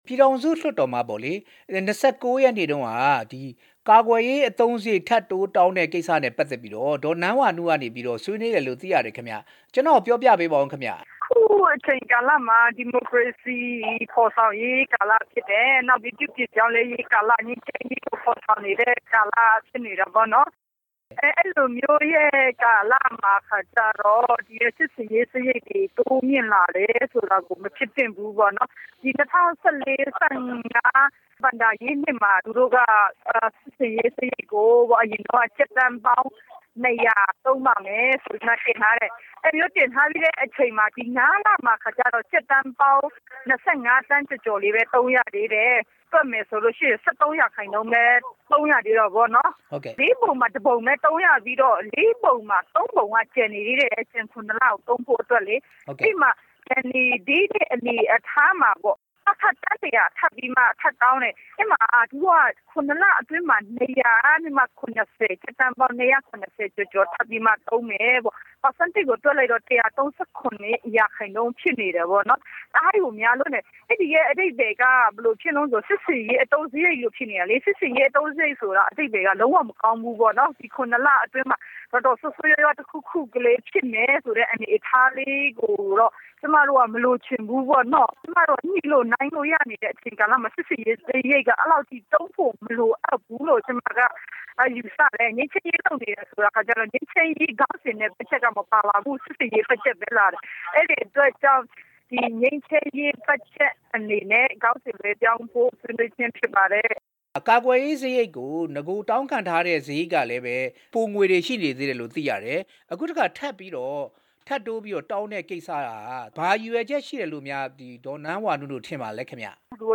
ထပ်တိုးဘတ်ဂျက် တောင်းခံခဲ့တာနဲ့ ပတ်သက်ပြီး မေးမြန်းချက်